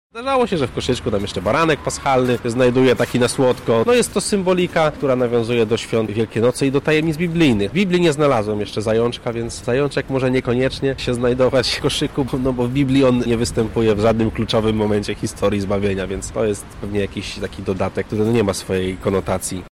mówi ksiądz